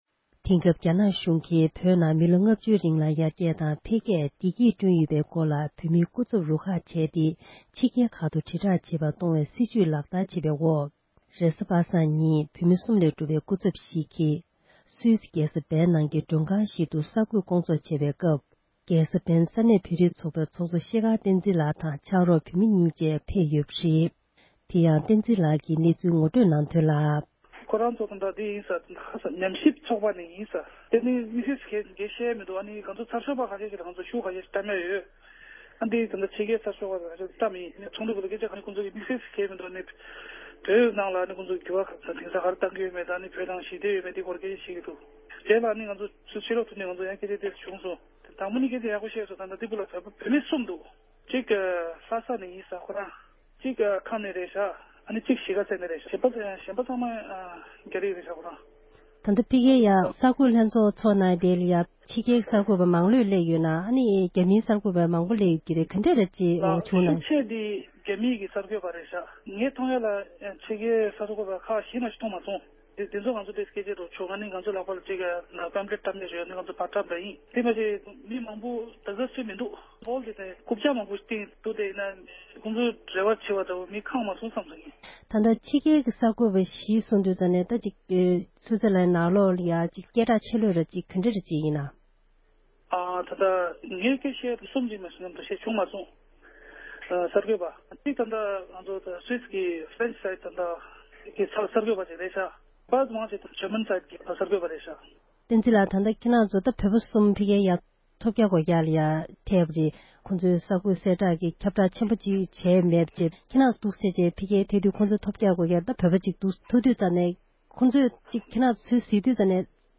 འབྲེལ་ཡོད་མི་སྣར་བཀའ་འདྲི་ཞུས་པ་ཞིག་གསན་རོགས་ཞུ༎